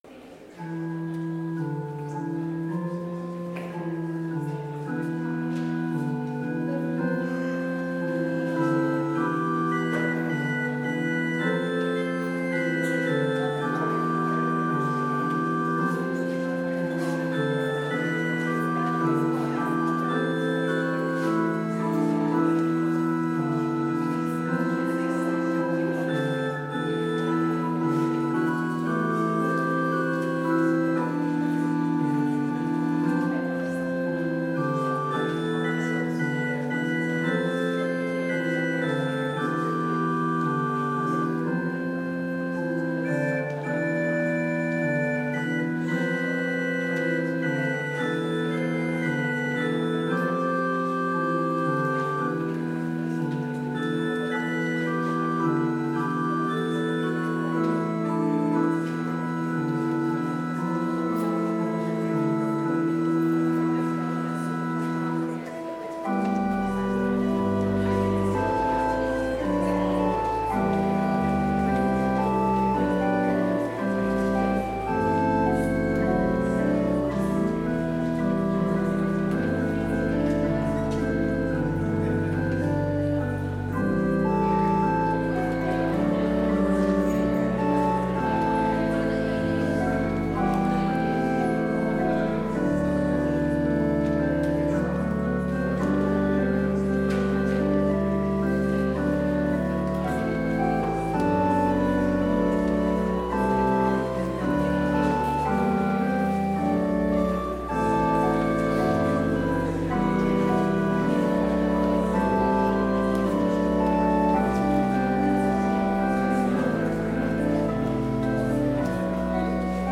Complete service audio for Chapel - December 9, 2021